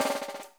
3B SN ROLL-R.wav